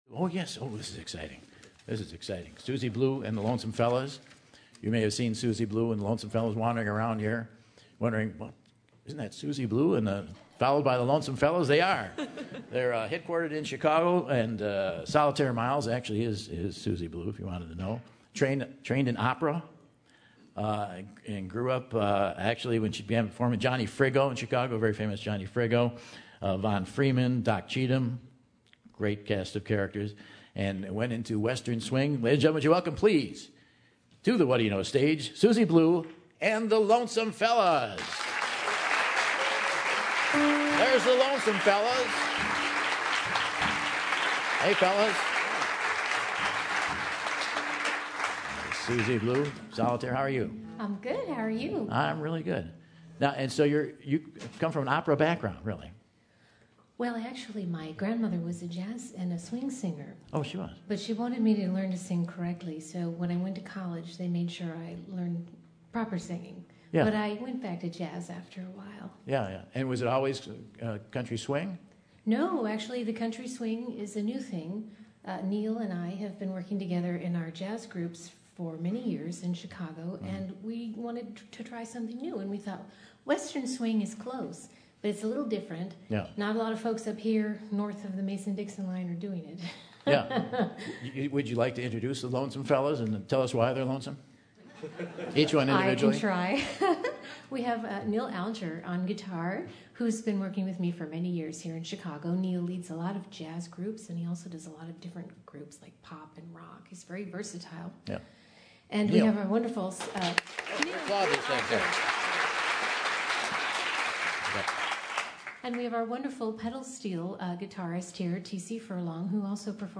country swing sound